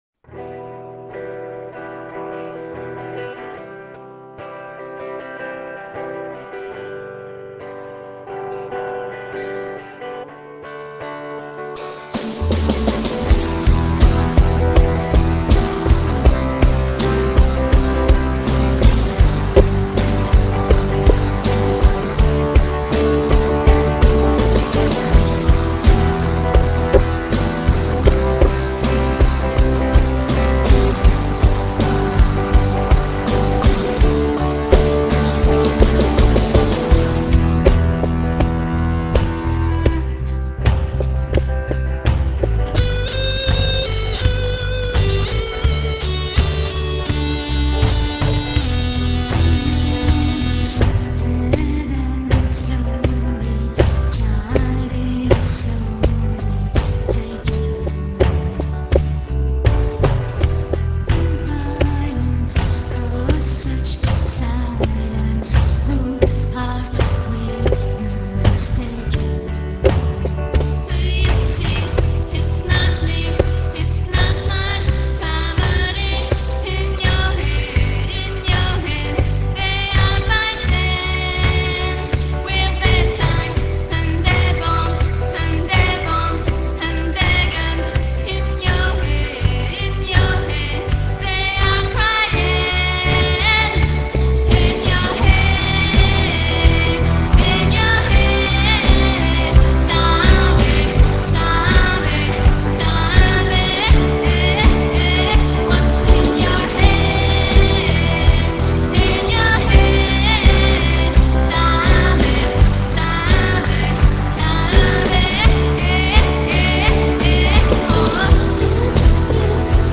reprise